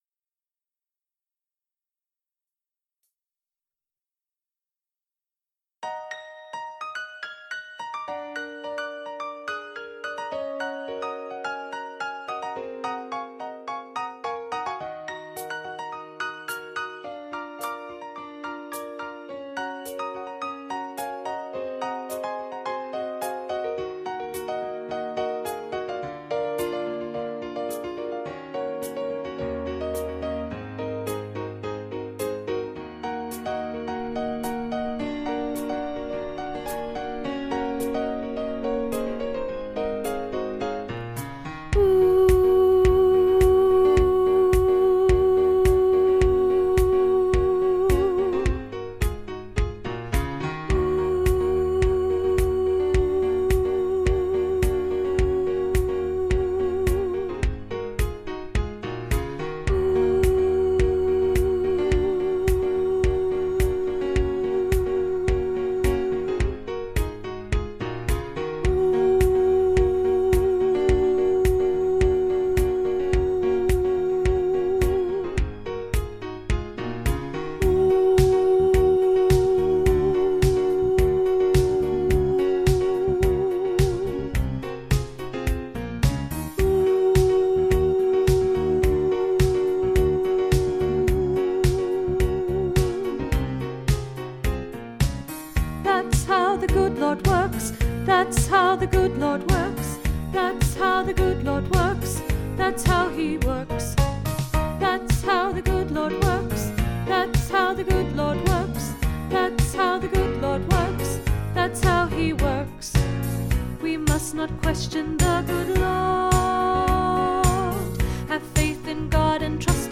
Stand on the Word Alto